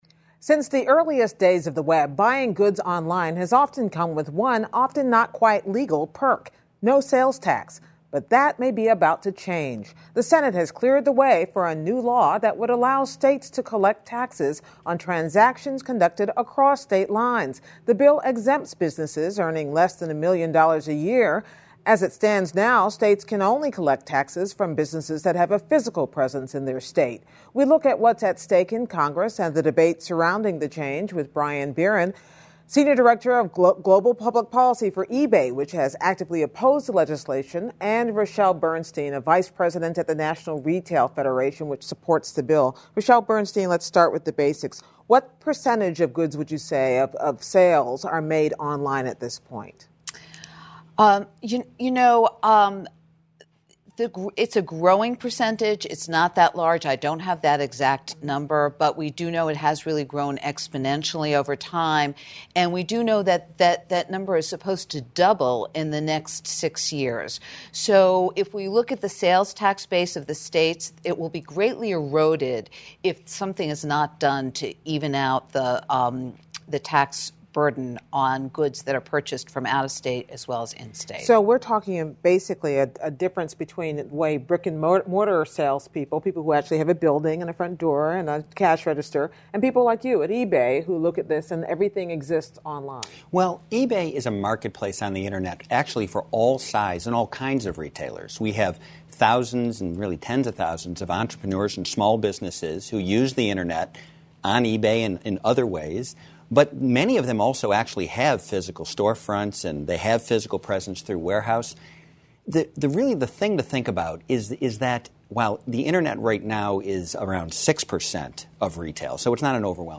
英语访谈节目:国会试图向网购消费者征收消费税